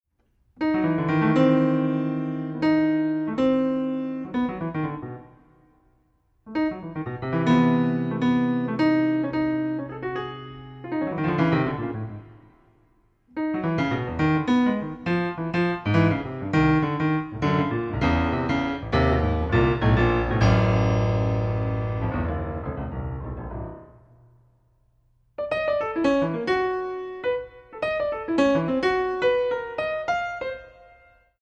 Solo Piano Concert
Recording: Ralston Hall, Santa Barbara, CA, January, 2008
Piano
Soundclip:  The piece begins with a free improvisation